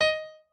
pianoadrib1_51.ogg